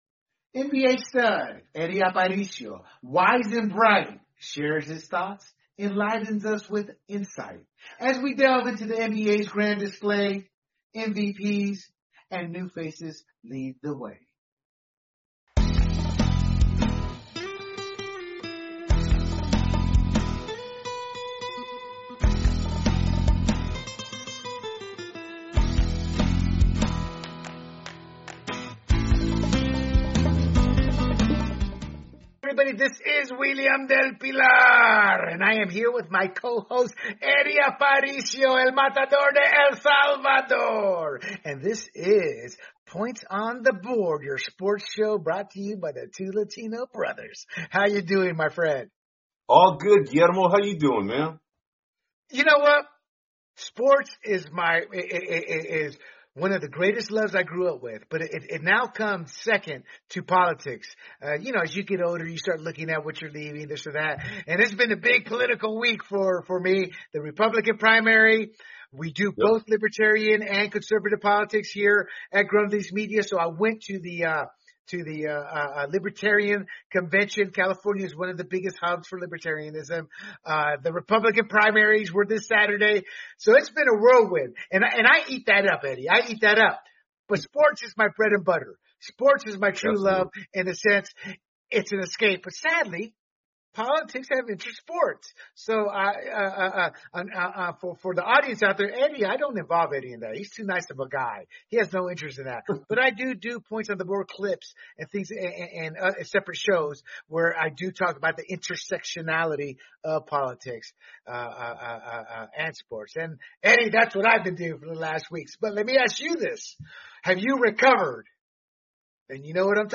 NBA analysts